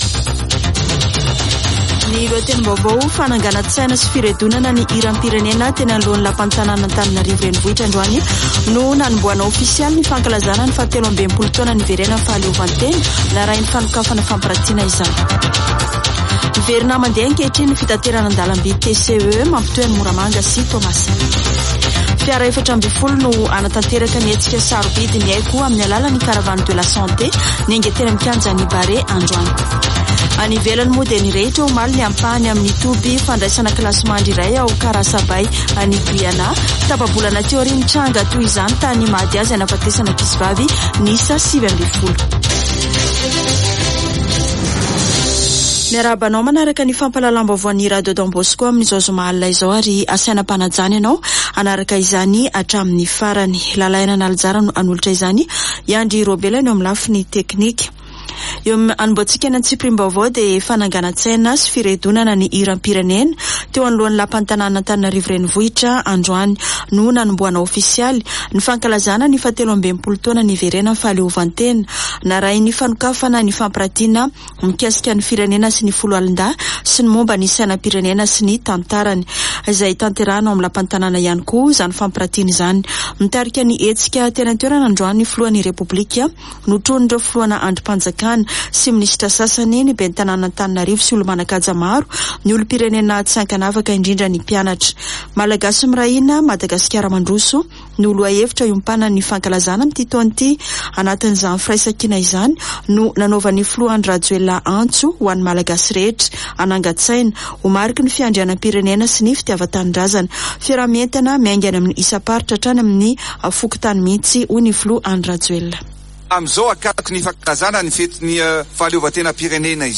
[Vaovao hariva] Zoma 2 jona 2023